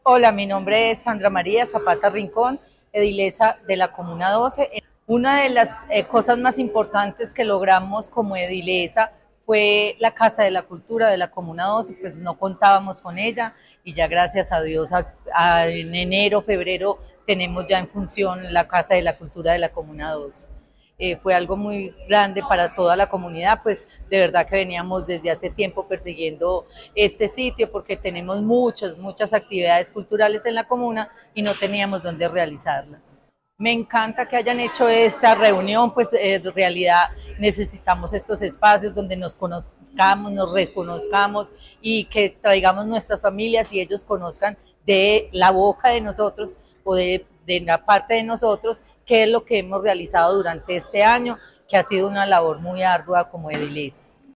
Palabras de Sandra Zapata, edila de la comuna 12
Este martes, el Distrito celebró el Día de las Juntas Administradoras Locales (JAL), un espacio que resalta el compromiso y la labor de los ediles y edilas en las 16 comunas y cinco corregimientos de la ciudad.